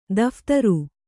♪ daphtaru